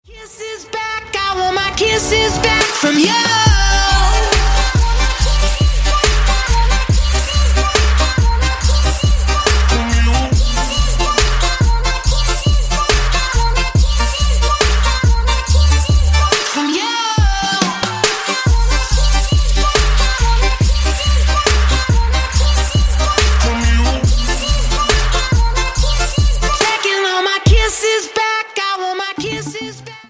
поп
dance